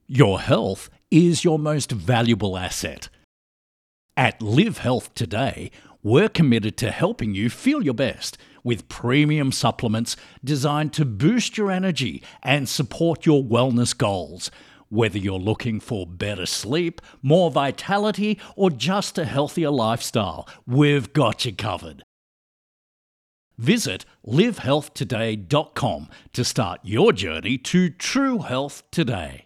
Cold read Live Health Today (Cold) Produced content Live Health Today